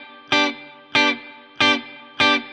DD_StratChop_95-Gmaj.wav